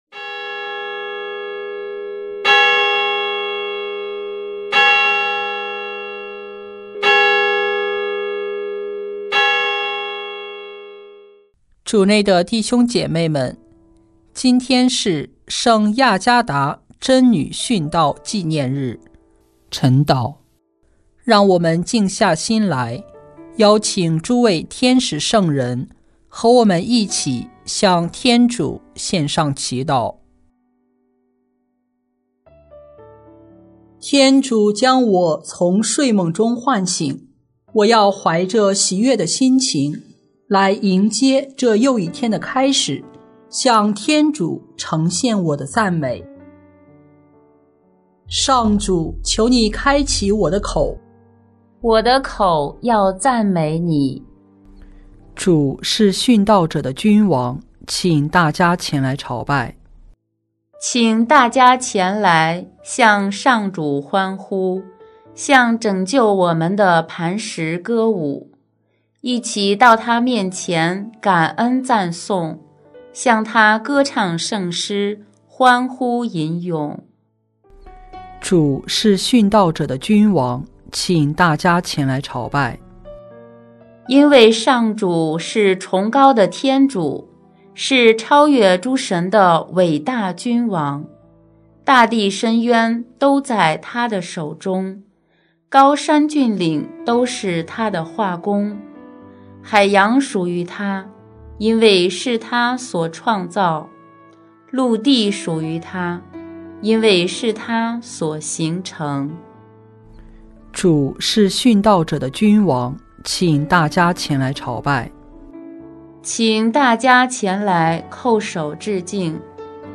【每日礼赞】|2月5日佳德（亚加大） 贞女殉道纪念日晨祷（第四周周四）